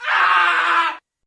Screaming Shorter Male Scream Sound Effect Free Download
Screaming Shorter Male Scream